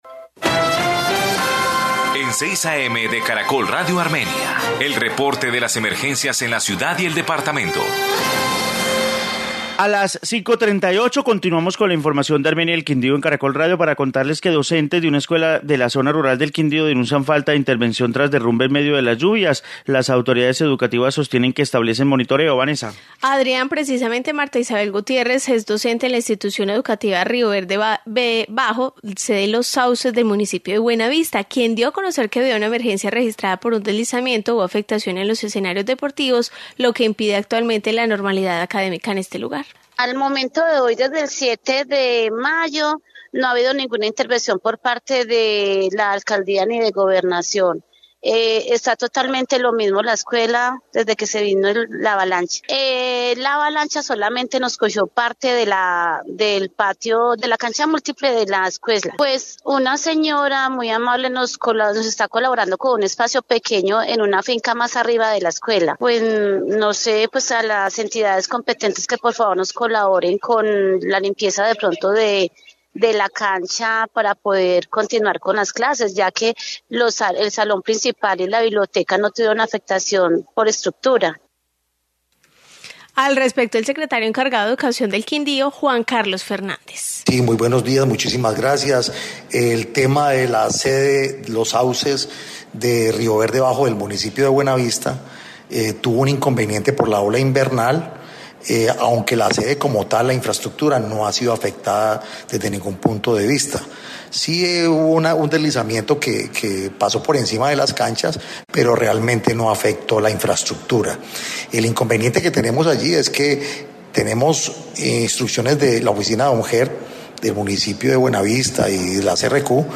Informe escuela Buenavista